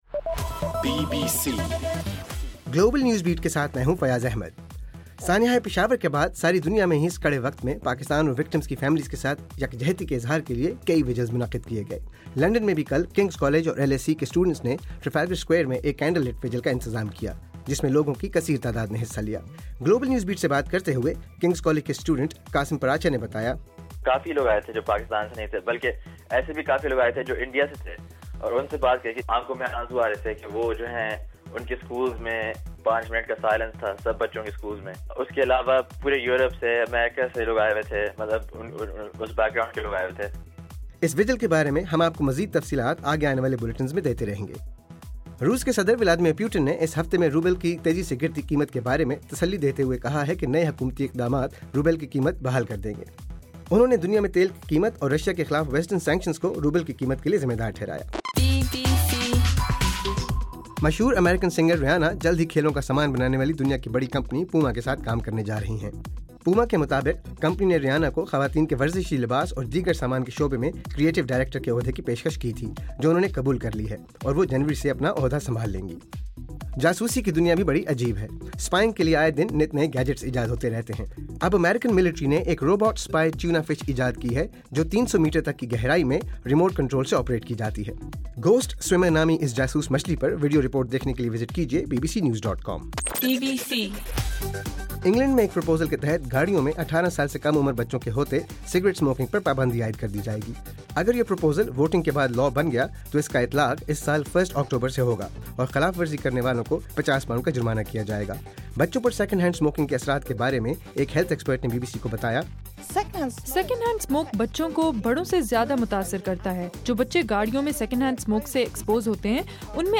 دسمبر 18: رات 9 بجے کا گلوبل نیوز بیٹ بُلیٹن